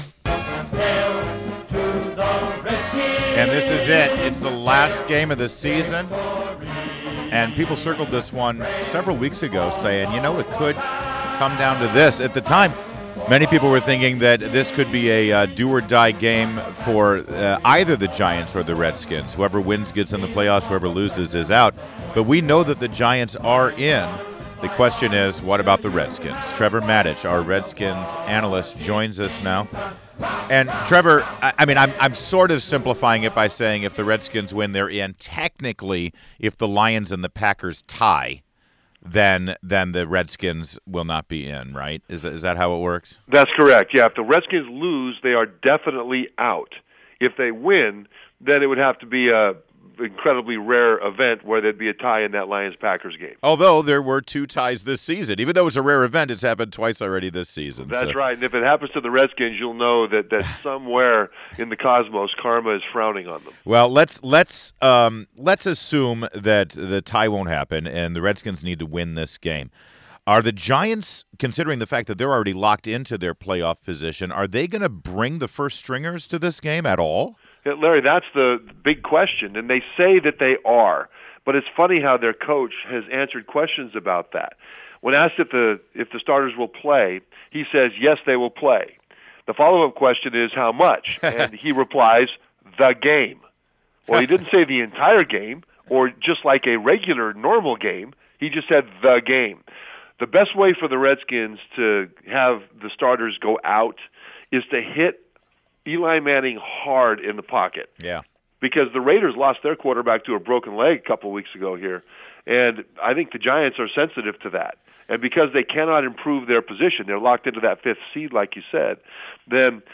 WMAL Interview- Trevor Matich 12.30.16